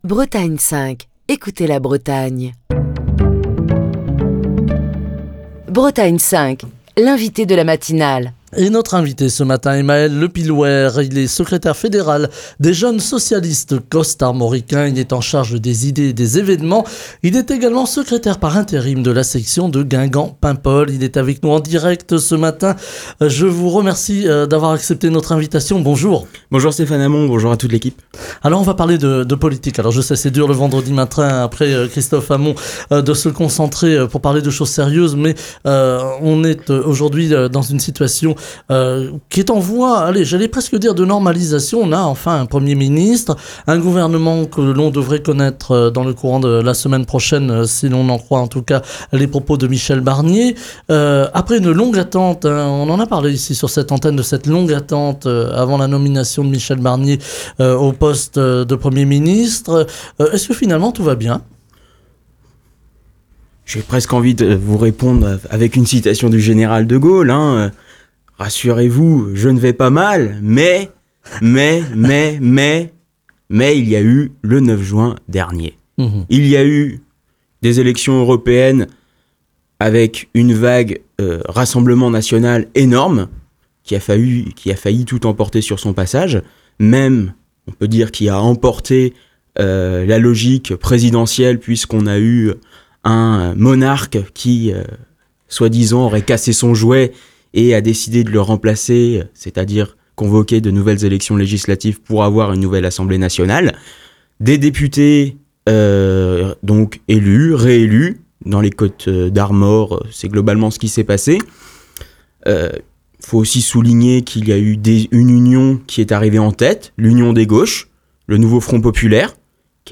Il souligne aussi le sentiment d'abandon des français, qui s'inquiètent du recul des services publics, notamment dans les zones rurales, et ont adressé un message clair au Président de la République lors des dernières élections, où le vote Rassemblement national a nettement progressé. Écouter Télécharger Partager le podcast Facebook Twitter Linkedin Mail L'invité de Bretagne 5 Matin